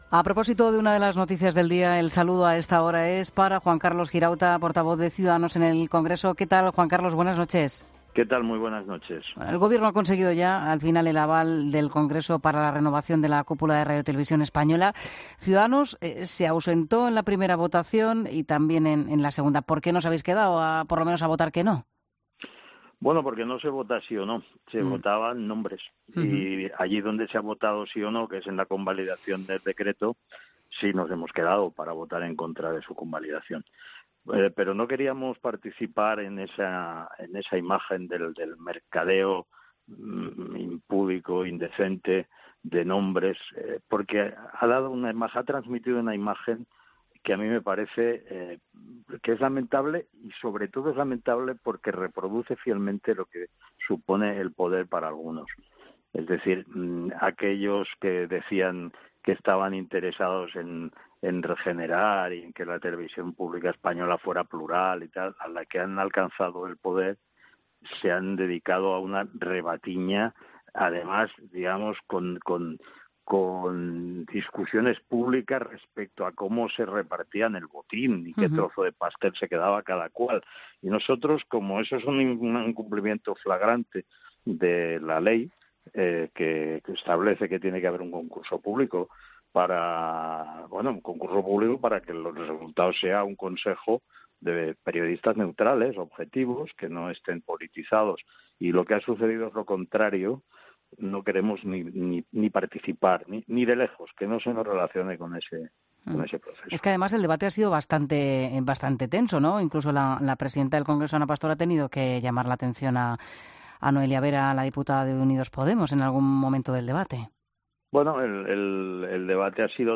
Así lo ha manifestado en los micrófonos de 'La Linterna' de la Cadena COPE, donde ha explicado por qué los miembros de su partido se han ausentado del pleno a la hora de votar los nombres de los futuros consejeros del ente público.